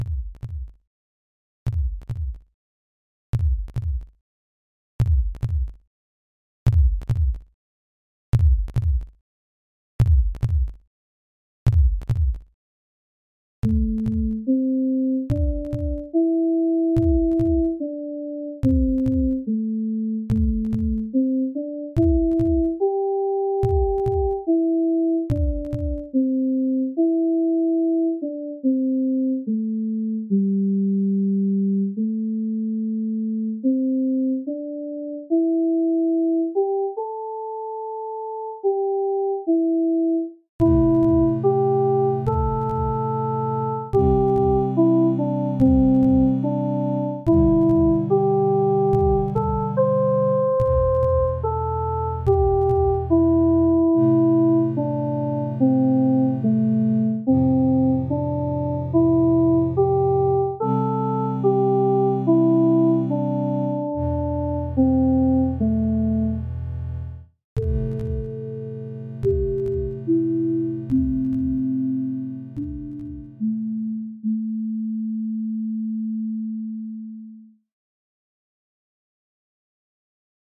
February 12, 2026 · 1:20 · A minor
Tempo: 72 BPM (resting heart rate).